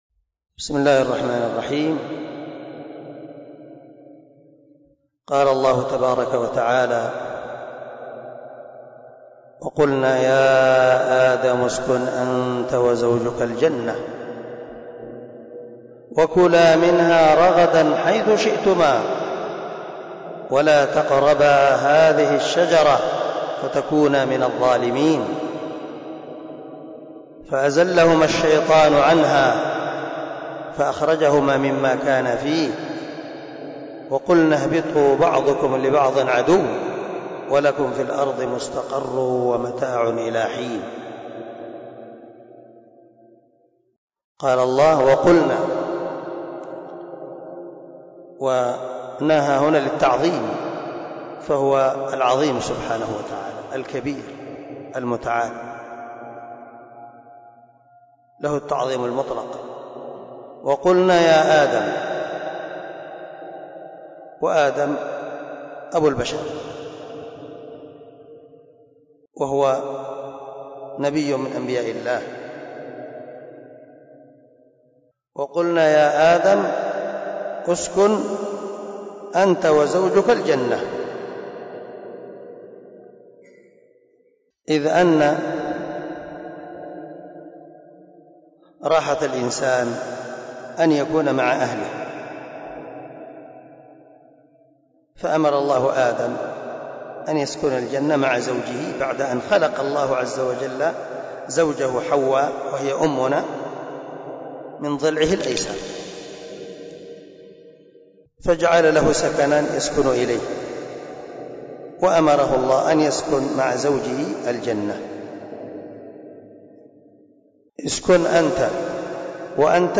024الدرس 14 تفسير آية ( 35 - 36 ) من سورة البقرة من تفسير القران الكريم مع قراءة لتفسير السعدي